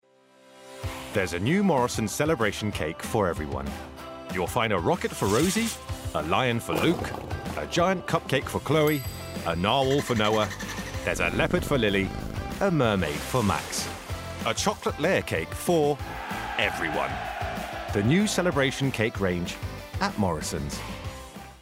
• Male
Showing: Commerical Clips
Bubbly, Happy, Upbeat